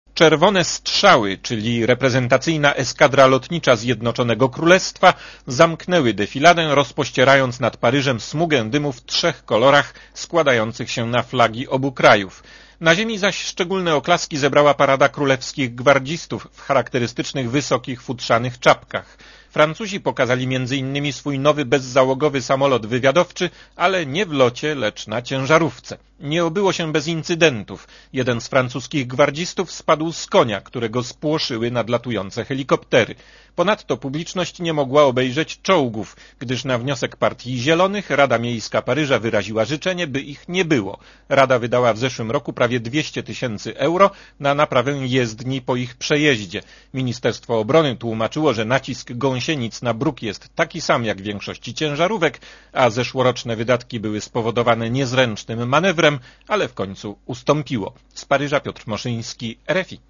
Korespondencja z Paryża